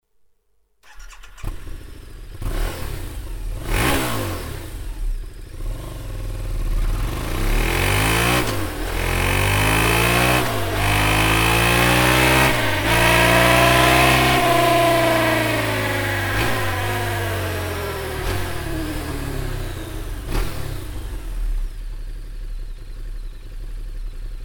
Sound Serienauspuff